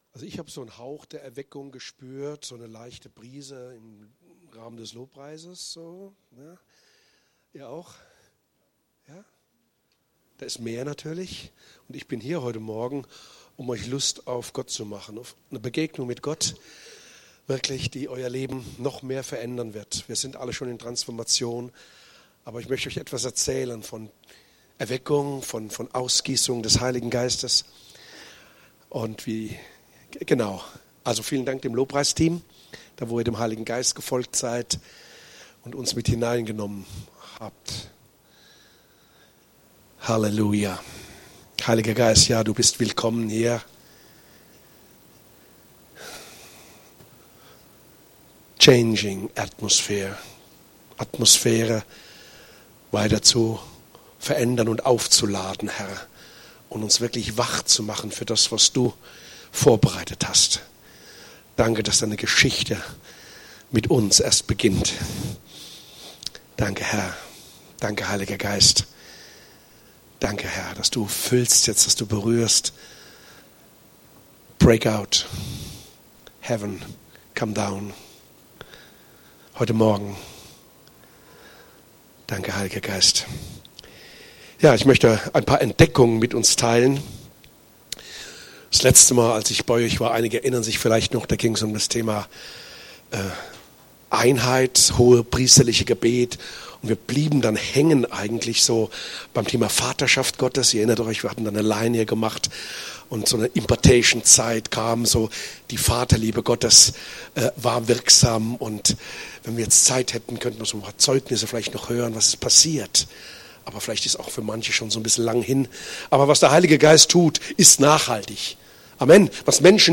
Aktuelle Predigten aus unseren Gottesdiensten und Veranstaltungen